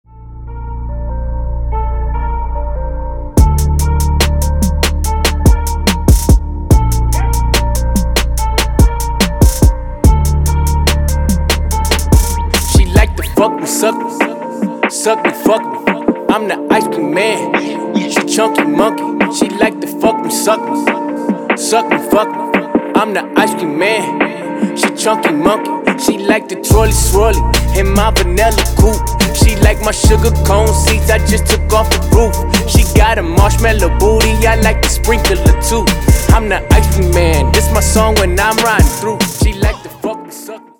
• Качество: 320, Stereo
мужской вокал
Хип-хоп
качающие
Bass